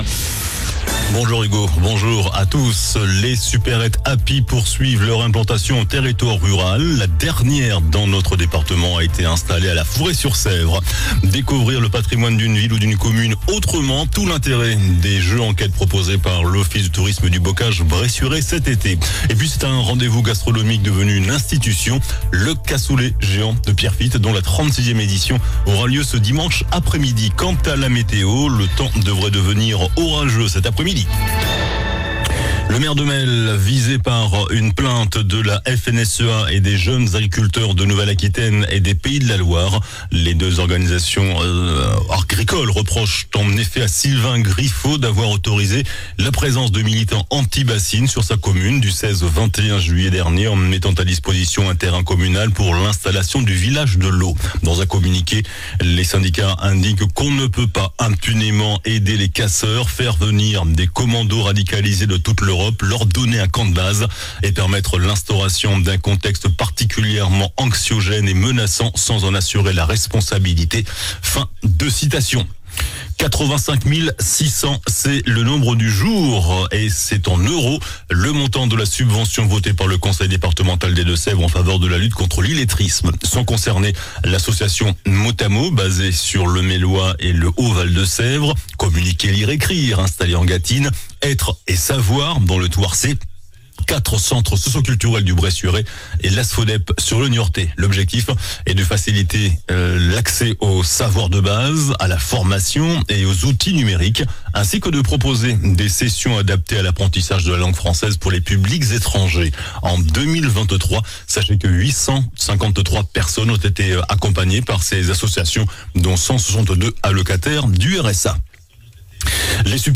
JOURNAL DU MERCREDI 31 JUILLET ( MIDI )